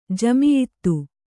♪ jamiyittu